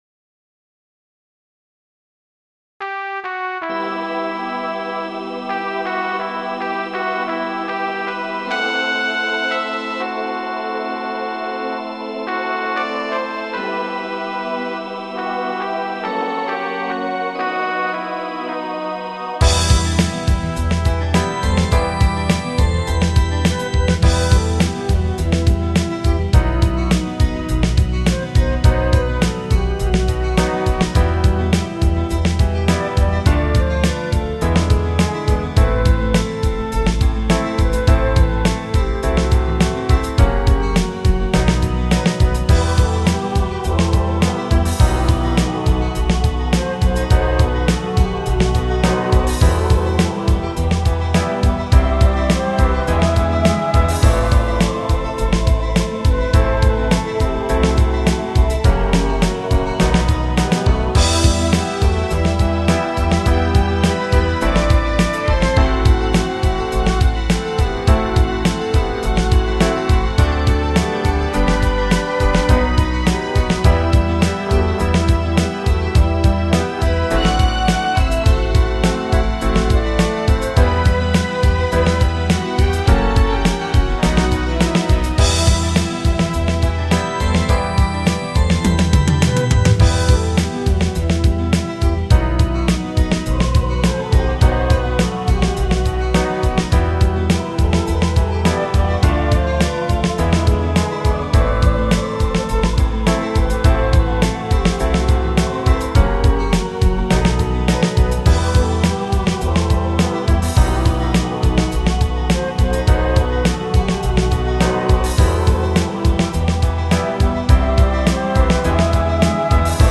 Мелодия